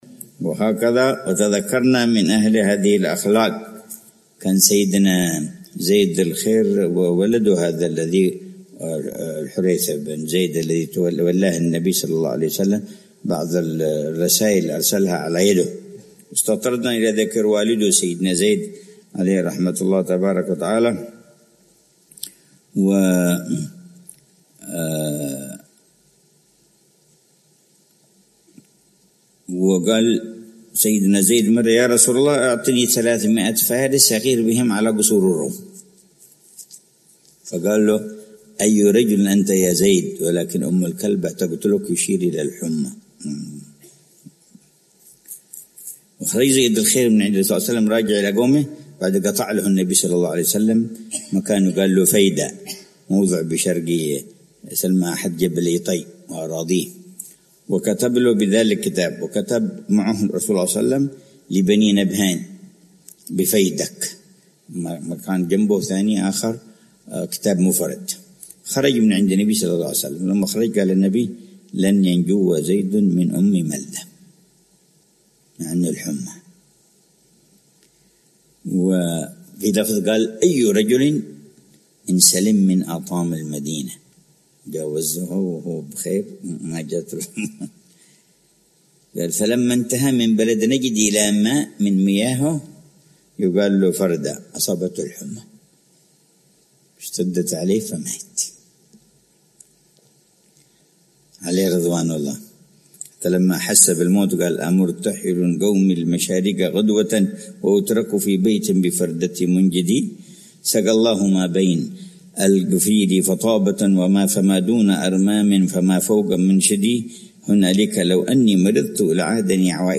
من دروس السيرة النبوية التي يلقيها العلامة الحبيب عمر بن محمد بن حفيظ، ضمن دروس الدورة التعليمية الحادية والثلاثين بدار المصطفى بتريم للدراسات